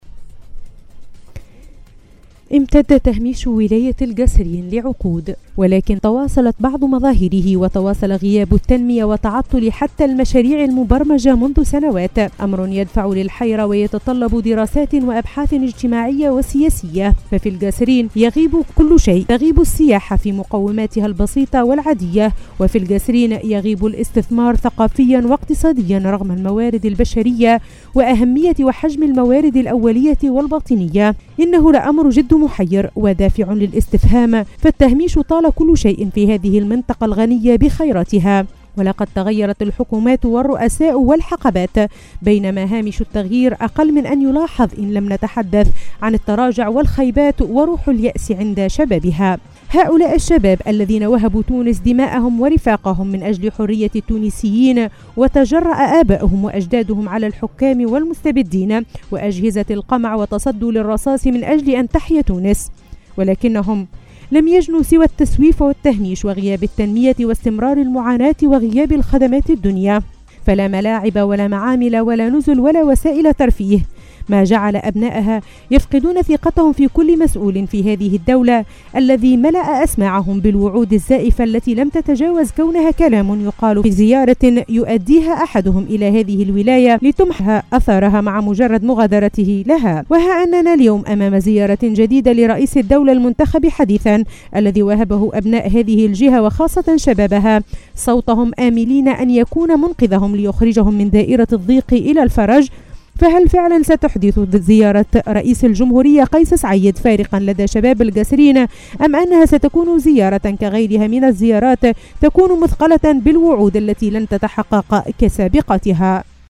وفي هذا السياق نمرر هذا التقرير